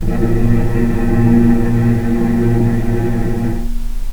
healing-soundscapes/Sound Banks/HSS_OP_Pack/Strings/cello/ord/vc-A#2-pp.AIF at 48f255e0b41e8171d9280be2389d1ef0a439d660
vc-A#2-pp.AIF